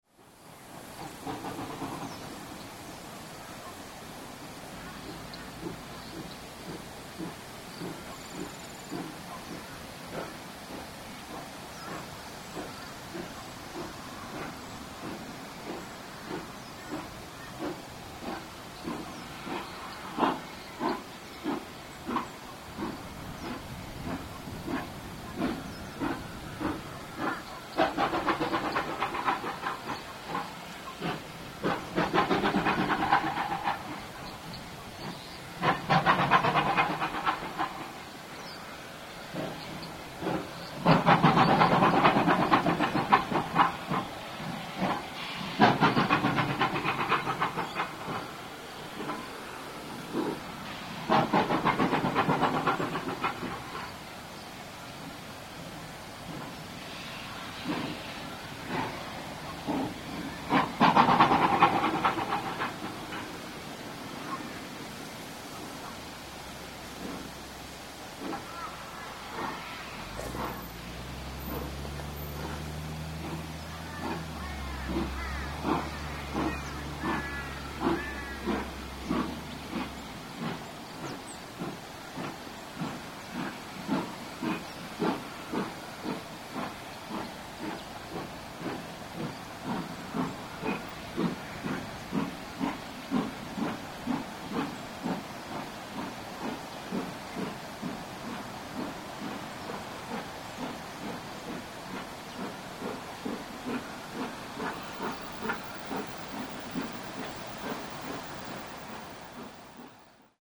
On the return, the drizzle returned, and this meant for a tough climb up Kandanga bank.
I was sure that the engine was going to stall, and as you can hear by this excerpt, it does not sound promising, but skillful driving and firing saw the train crest the hill.